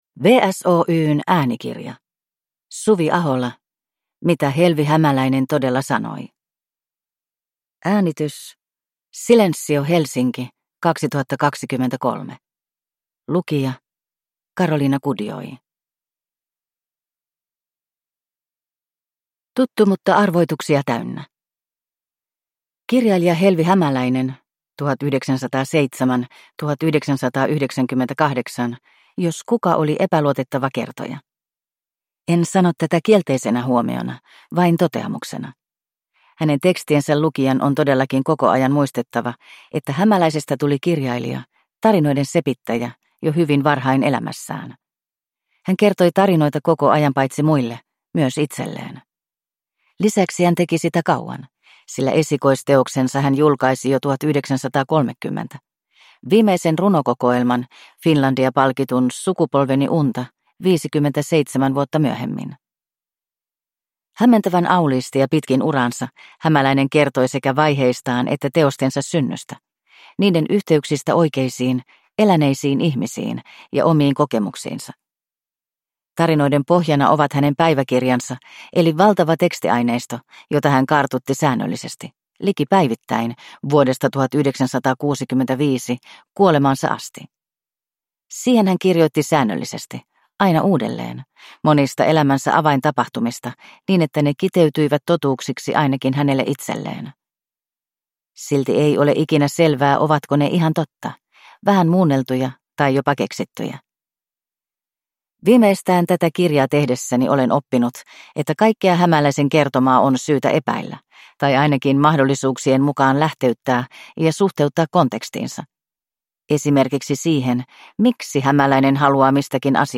Mitä Helvi Hämäläinen todella sanoi? – Ljudbok – Laddas ner